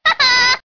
One of Princess Daisy's voice clips in Mario Kart DS